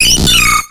Audio / SE / Cries / MARILL.ogg